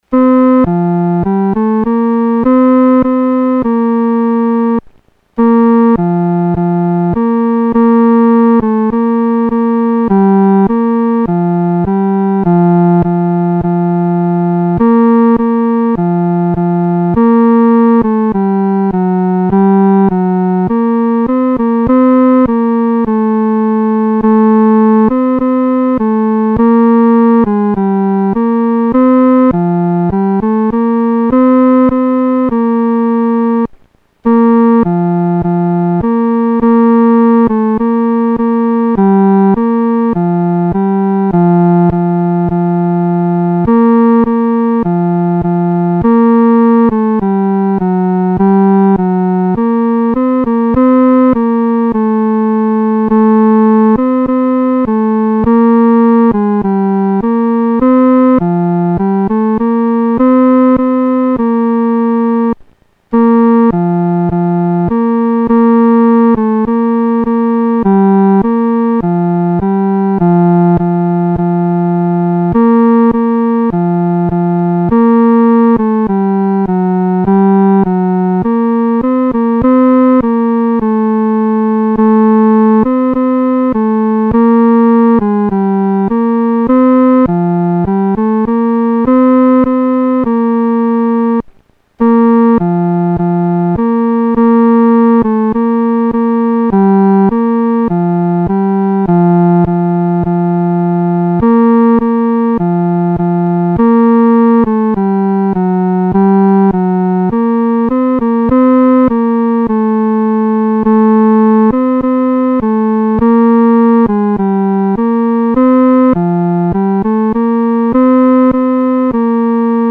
独奏（第三声）